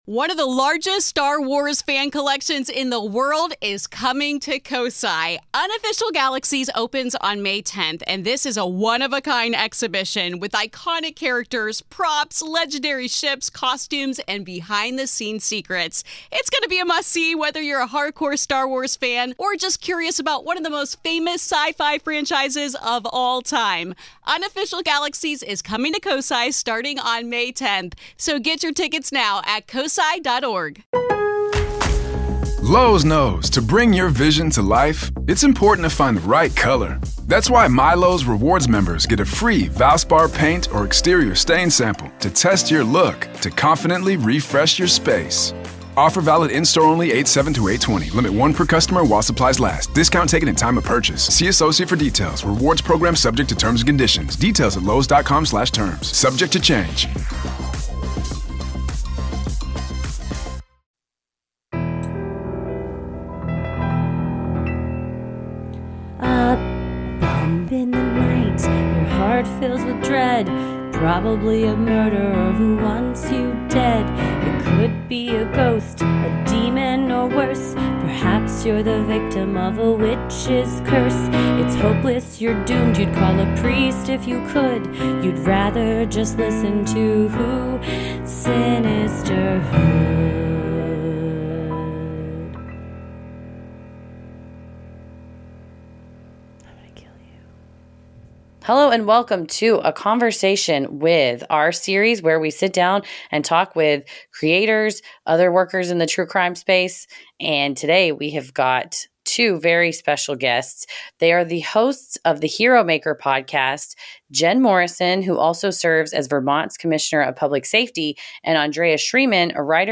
We talk with them about their journey from college classmates to starting the show, the impact of true crime media on policy change, how sudden violent acts change so many of those involved, the healing power of sharing stories, and so much more. Organizations mentioned in the interview: